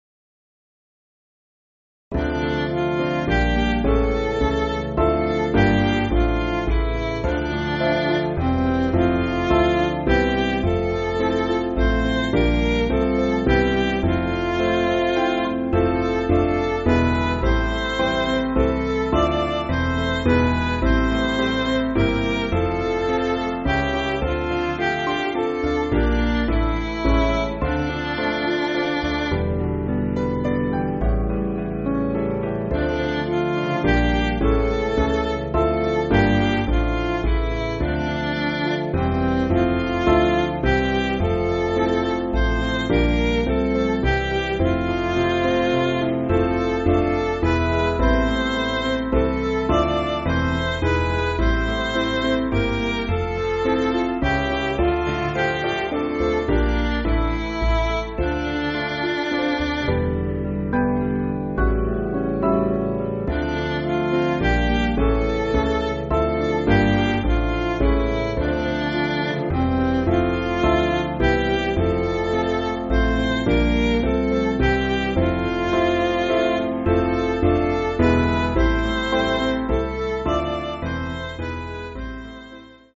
Small Band
Piano & Instrumental
Midi